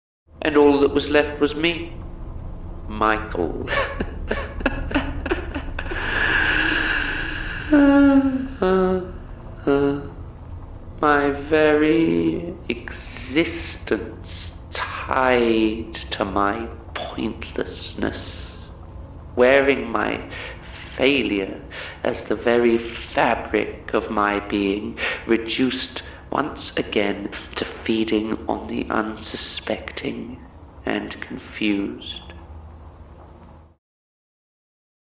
i love his silly little laugh he is so horrifying